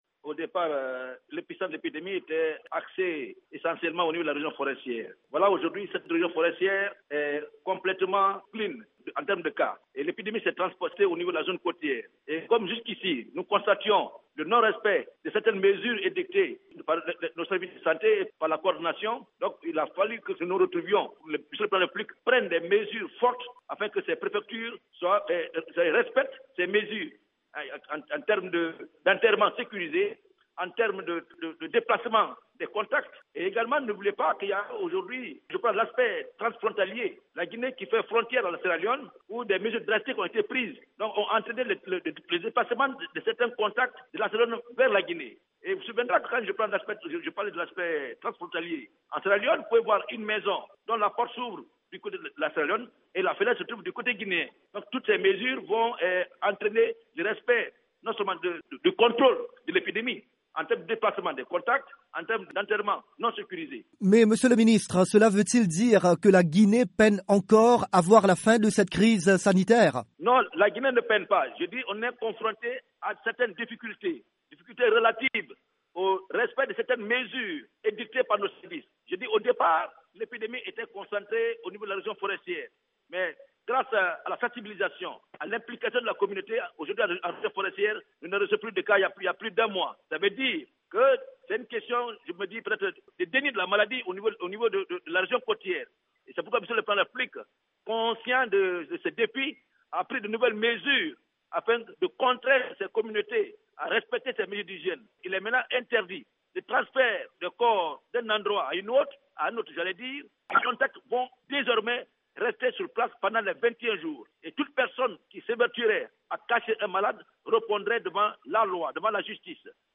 "La Guinée ne peine pas mais est confrontée à certaines difficultés", explique à VOA Afrique, le docteur Rémy Lamah, ministre guinéen de la Santé.